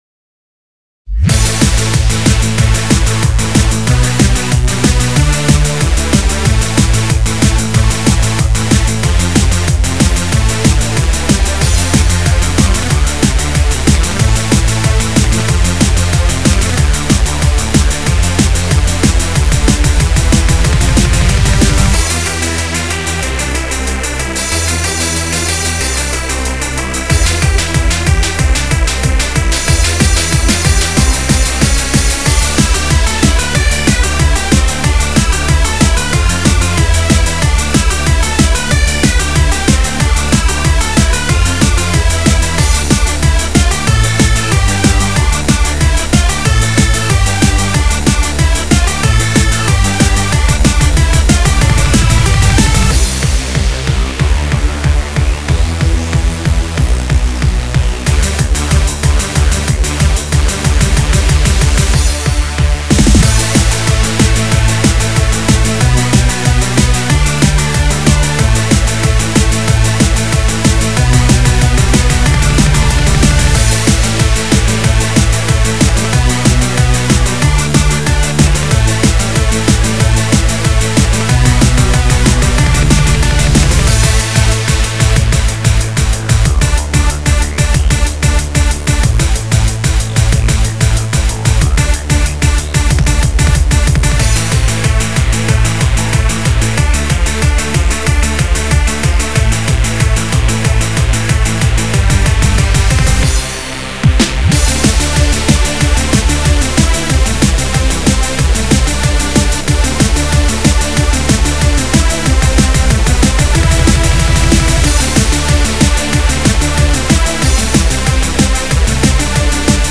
BPM186-372
Genre: Freeform Hardcore